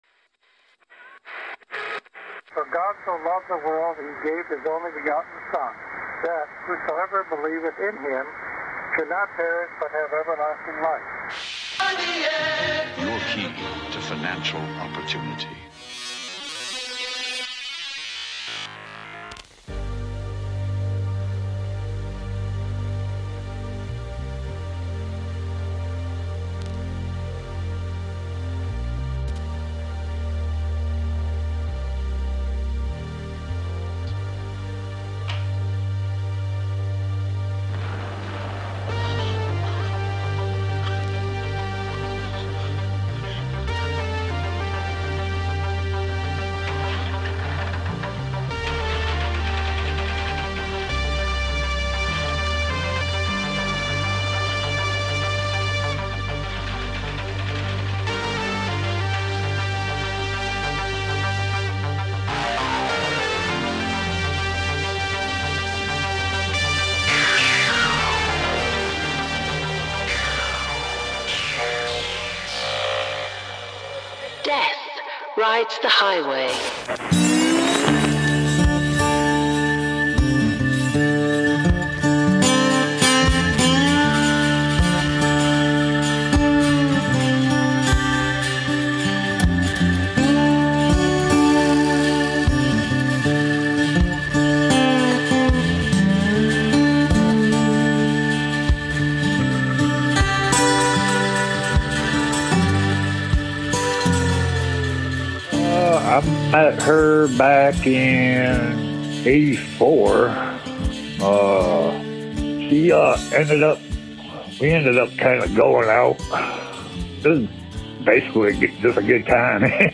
True Crime Narratives